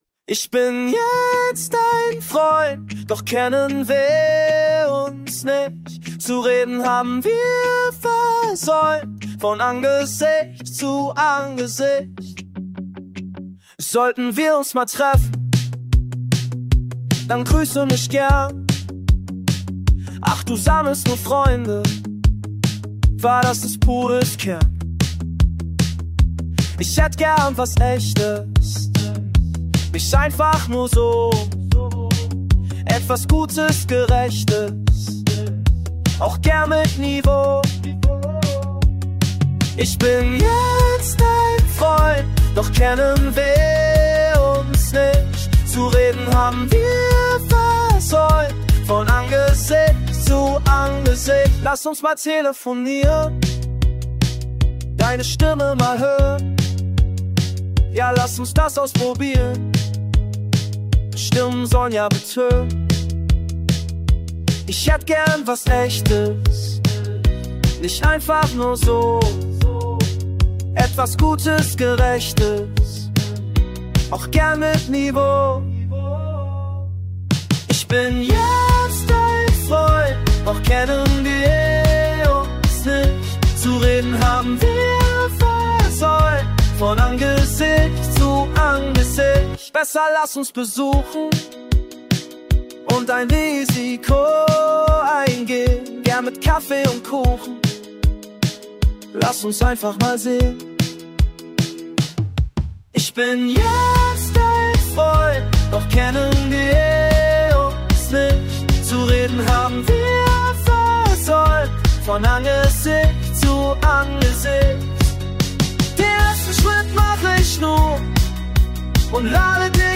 Indy Pop